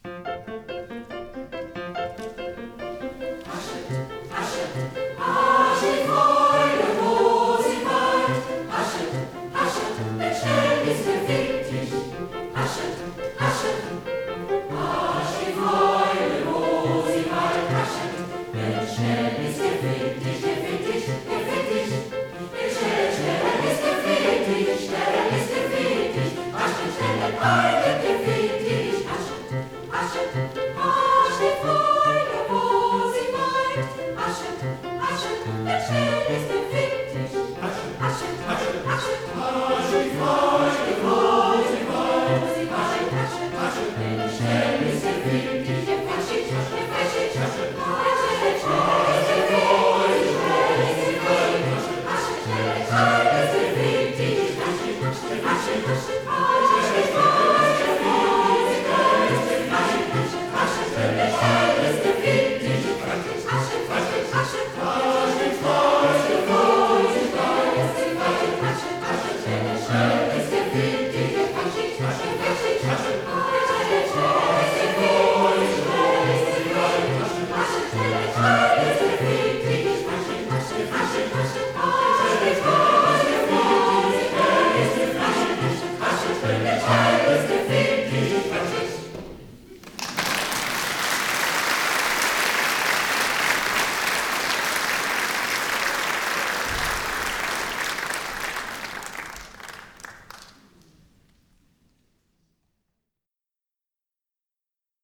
der pfiffig-schnelle Haydn – Kanon
Wir sangen ihn bei unserem Konzert „Holder Frühling“ im Jahre 2007 –